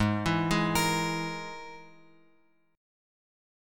G#sus2#5 chord {4 7 6 x x 6} chord